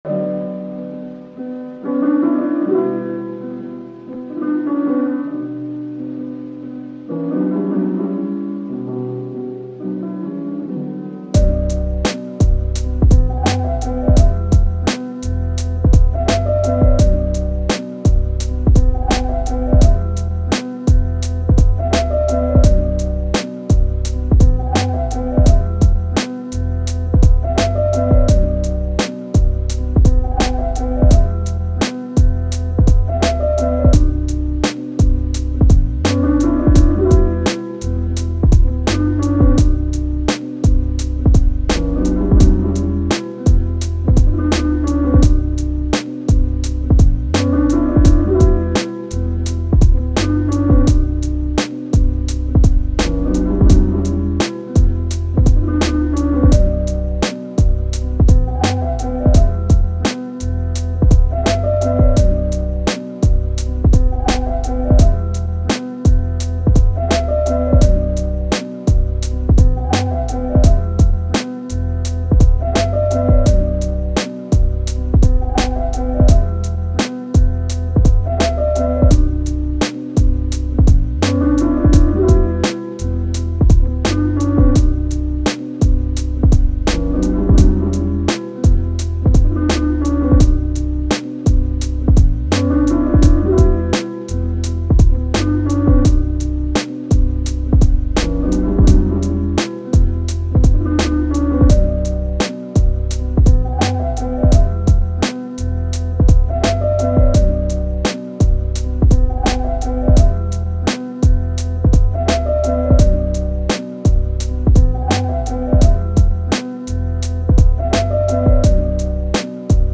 Медленная мелодия без слов